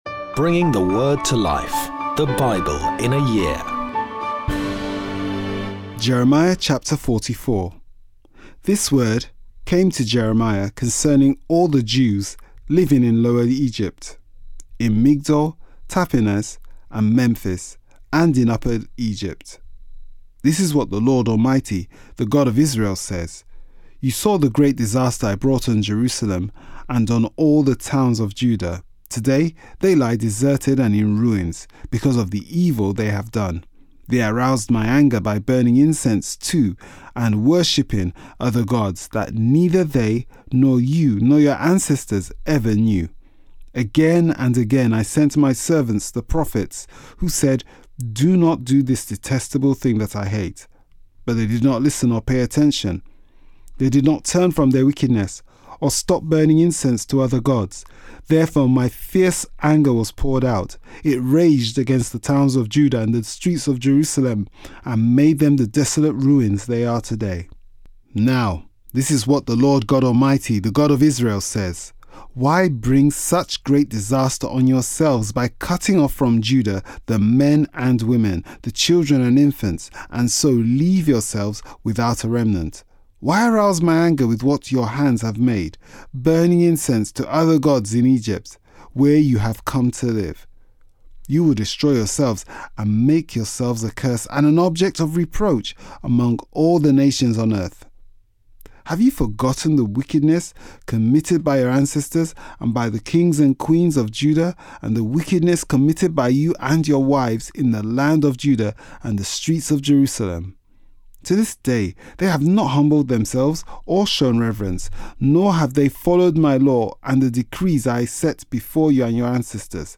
Today's readings come from Jeremiah 44-45; Psalms 13; Jeremiah 46-47